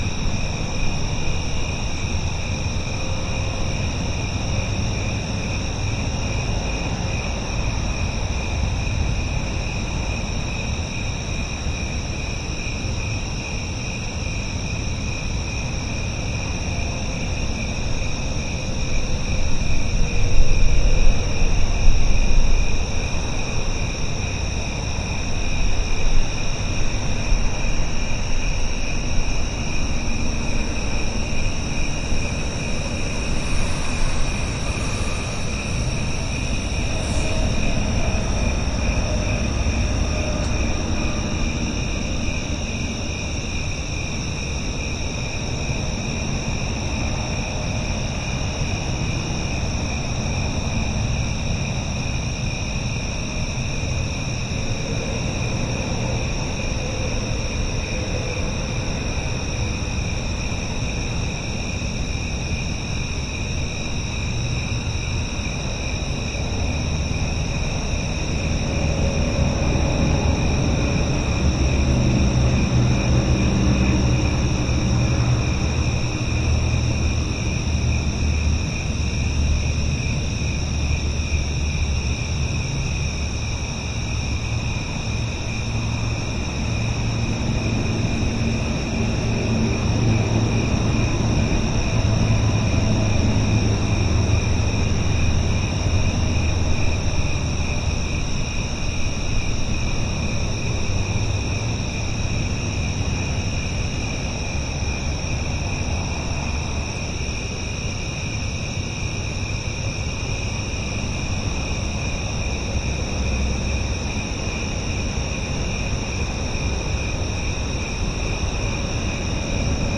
随机的" 蟋蟀的夜晚停车场的高速公路上远处的交通
Tag: 高速公路 通过 遥远 停车 很多 蟋蟀 交通 晚上